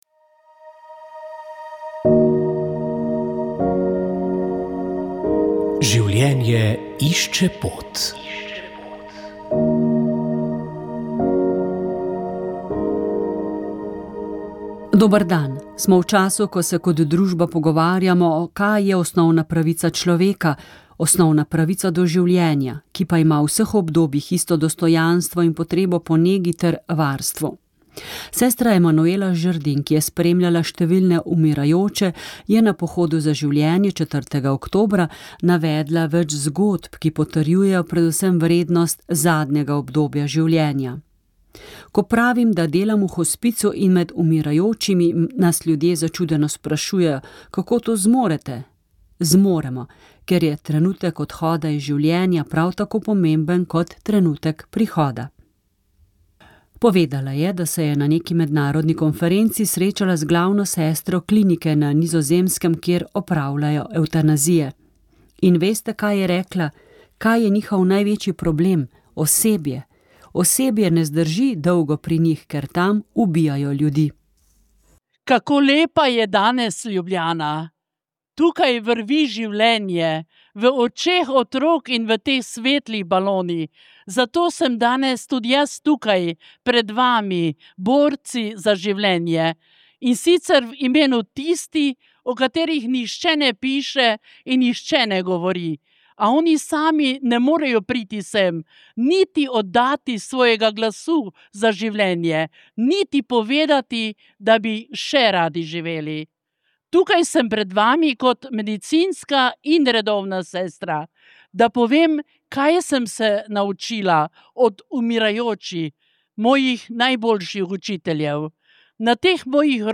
V odddaji smo potegnili črto pod uspešen dogovor o dodatnih sredstvih za področje kemtijstva v novem programskem obdobju. O tem smo poglobljen ogovor pripravili tudi s Cvetkom Zupančičem, kmetom, državnim svetnikom in prejšnjim predsednikom KGZS.